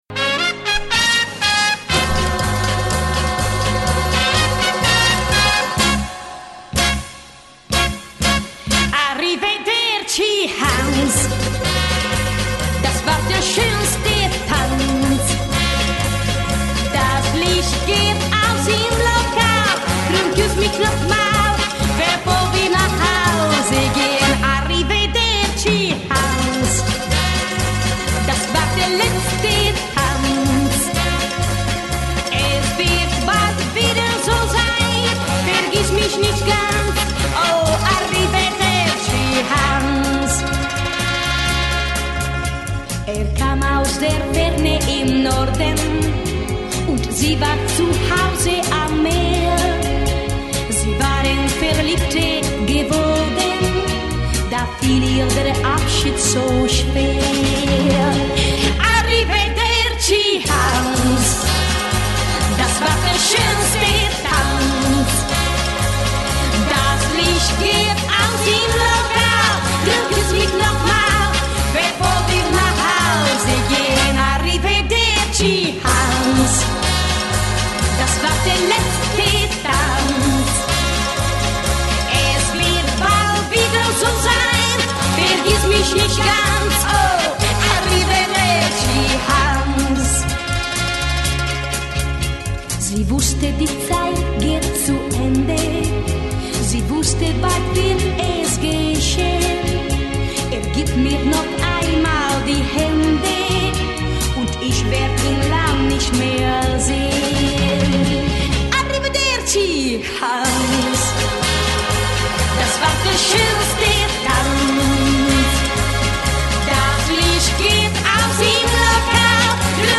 Просто - Оркестр.